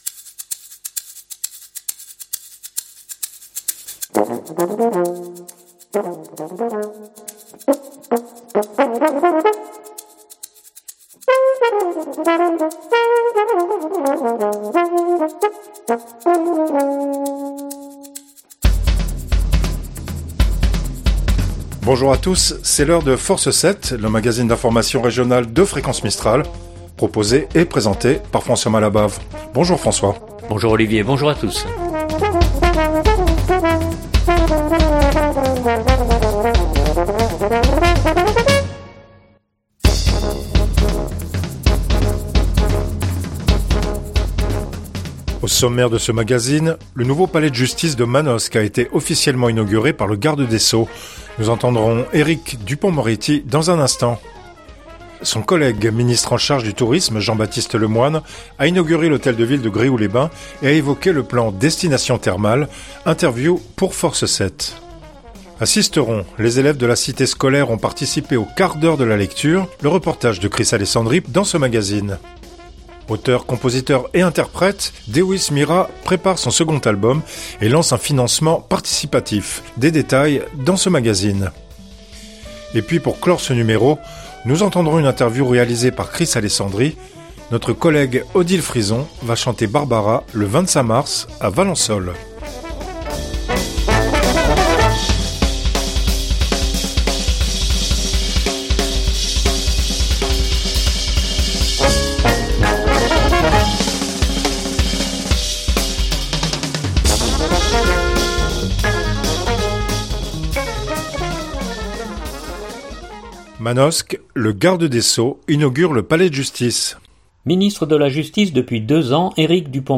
Interview pour Force 7.